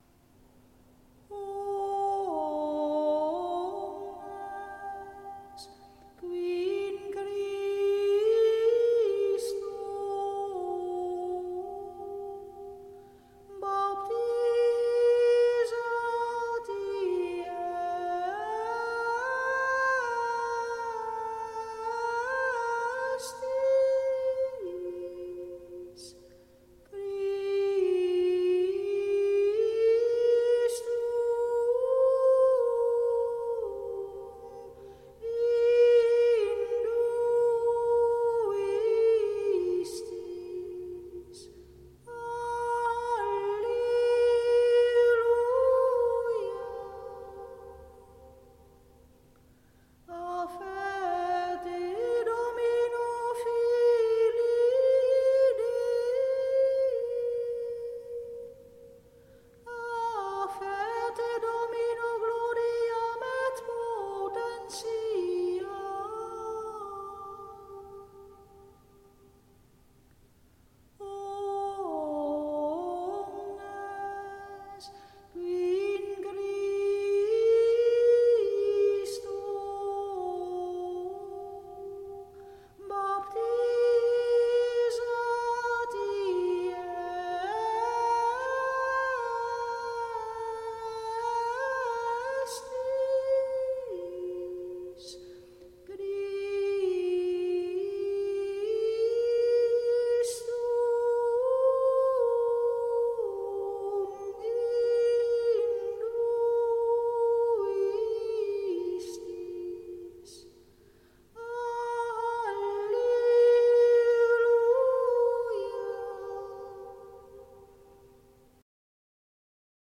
Geschiedenis Gregoriaans
Omnes qui in Christo baptizati estis (Gal. 3, 27), communio  WMP   RealPlayer